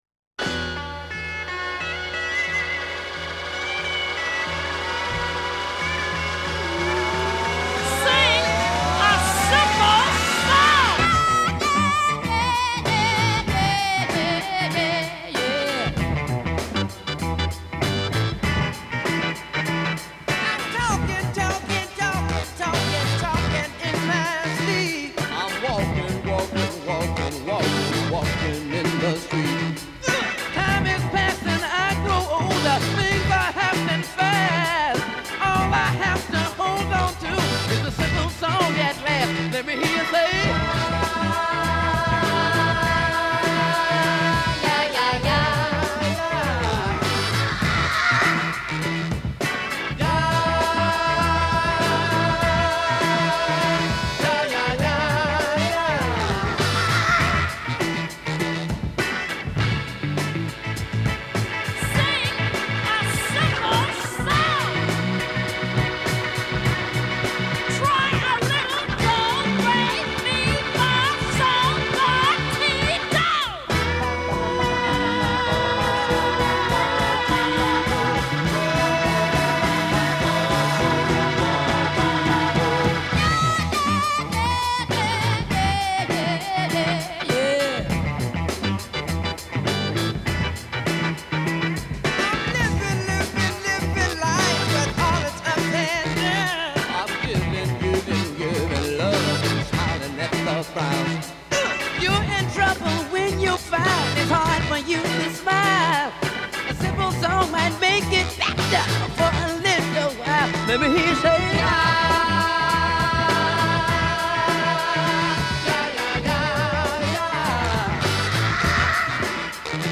is an absolute monster of E9 riffing